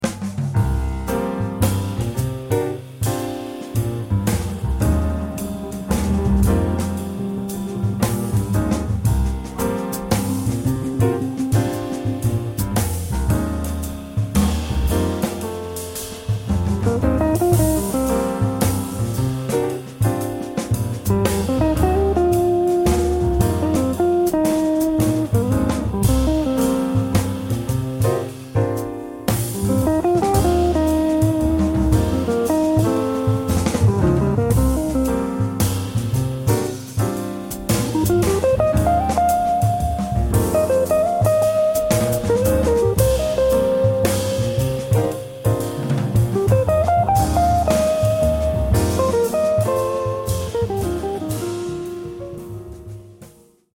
Swing (medium)